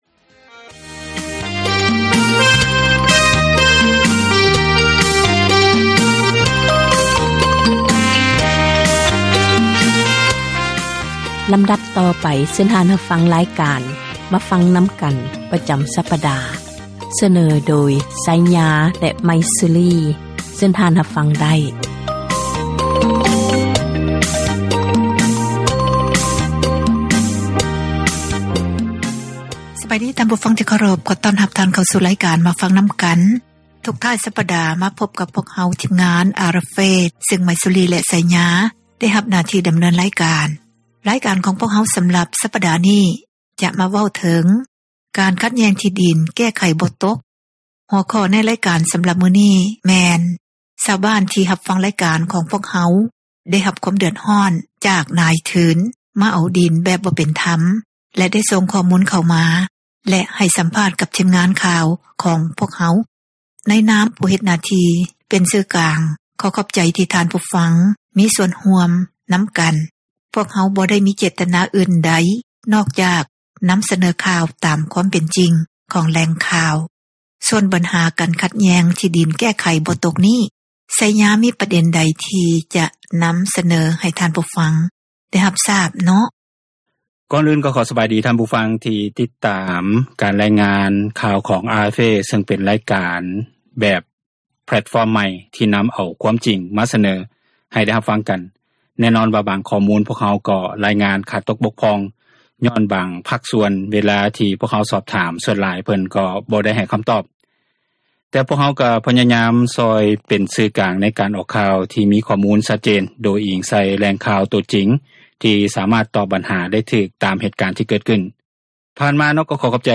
ຂໍ້ຂັດແຍ່ງທີ່ິດິນ ແກ້ໄຂບໍ່ຕົກ, ມີຊາວບ້ານຜູ້ໄດ້ຮັບຄວາມເດືອດຮ້ອນຈາກນາຍທືນ, ມາເອົາດິນແບບບໍ່ເປັນທັມໄດ້ສົ່ງຂໍ້ມູູນເຂົ້າມາ ແລະ ໃຫ້ສັມພາດກັບທີມງານຂ່າວ ຂອງພວກເຮົາ. ເຊີນທ່ານຮັບຟັງໄດ້ເລີຍ.
ການສົນທະນາ ໃນບັນຫາ ແລະ ຜົລກະທົບ ຕ່າງໆ ທີ່ເກີດຂຶ້ນ ຢູ່ ປະເທດລາວ